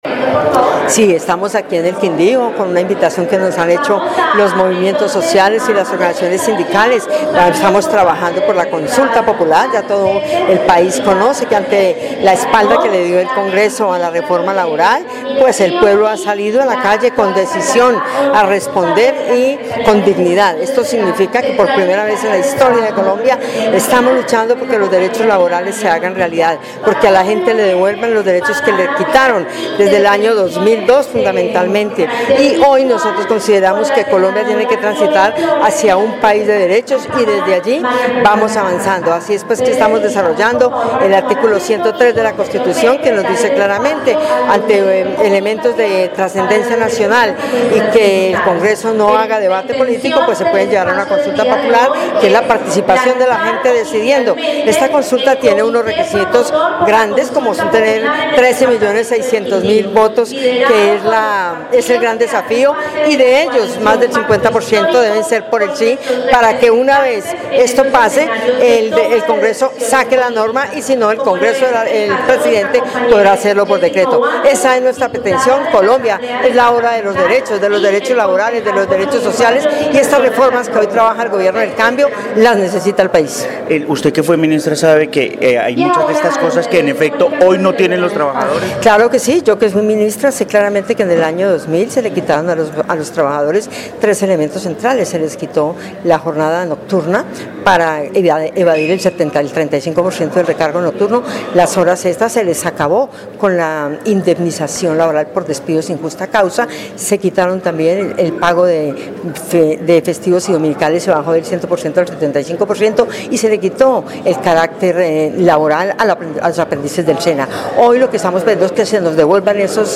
Gloría Inés Ramírez, exministra de trabajo